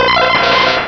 Cri de Noeunoeuf dans Pokémon Rubis et Saphir.